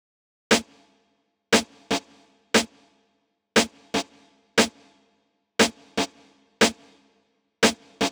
12 Snare.wav